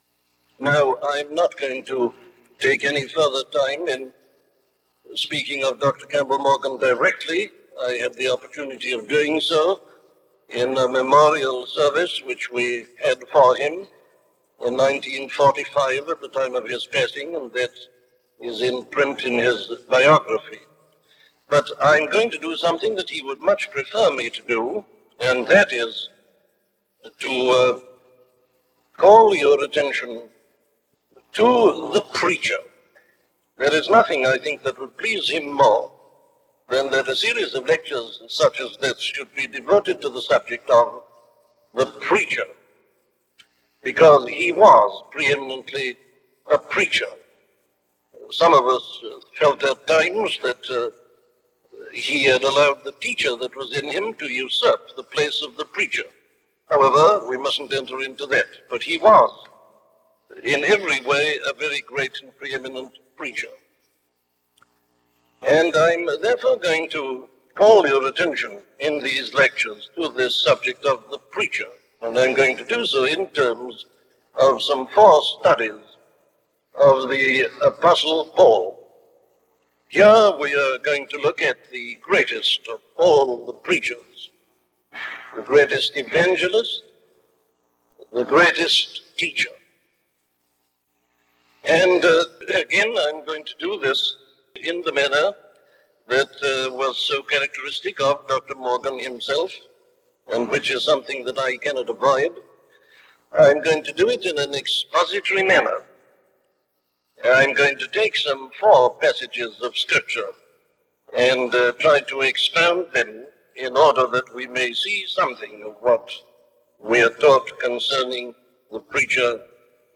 Free Sermon | Newly Found Sermons
A collection of sermons on Newly Found Sermons by Dr. Martyn Lloyd-Jones